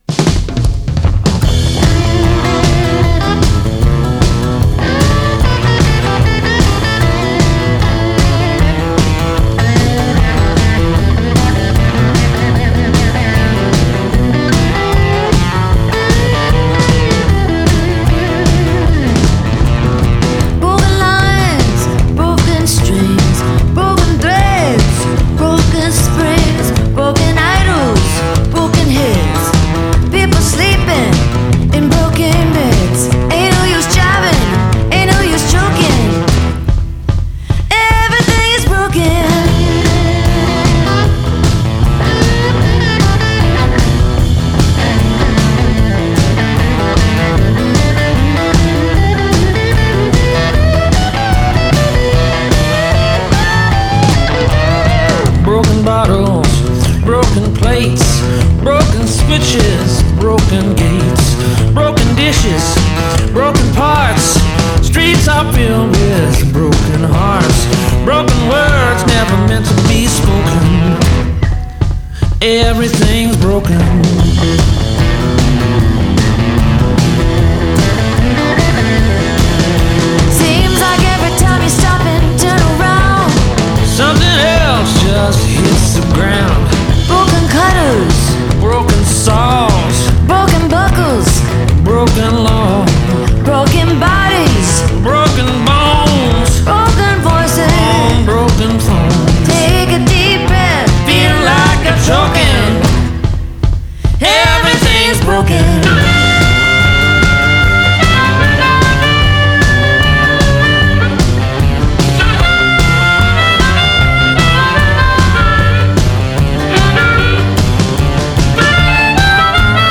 Genre: Country